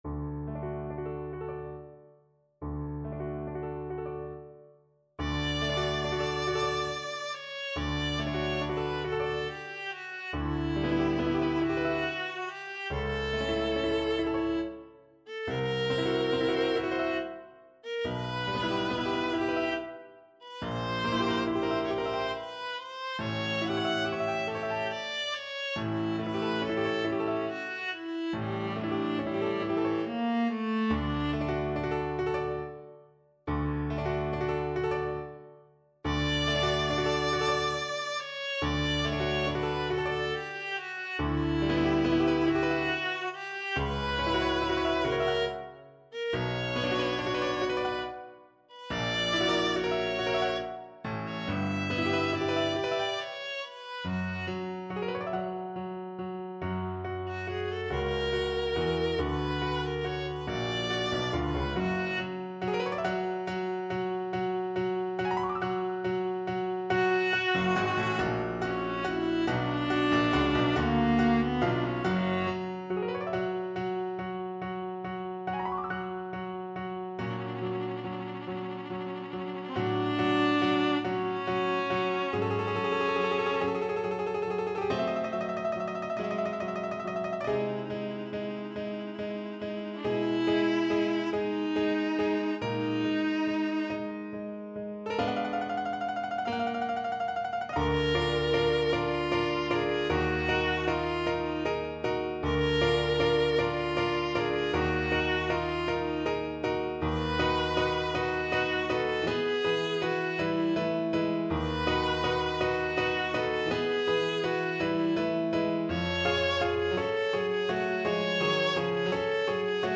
12/8 (View more 12/8 Music)
Adagio maestoso
Classical (View more Classical Viola Music)